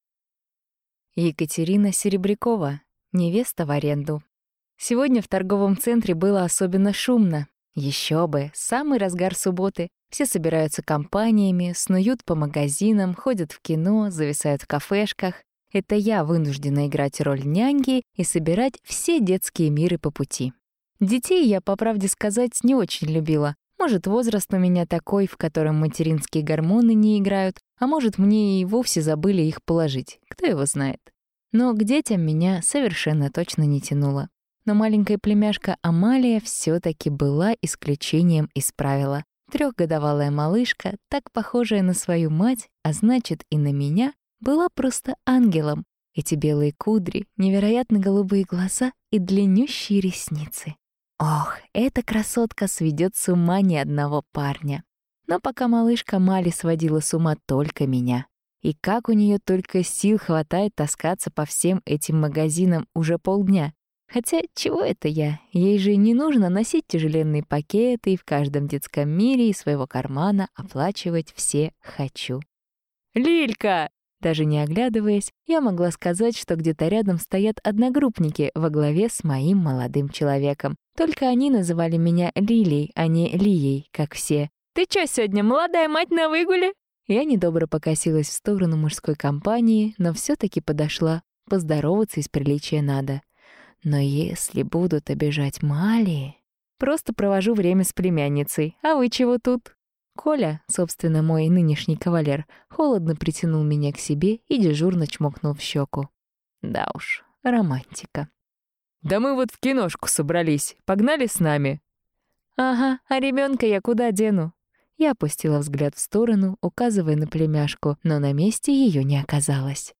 Аудиокнига Невеста в аренду | Библиотека аудиокниг
Прослушать и бесплатно скачать фрагмент аудиокниги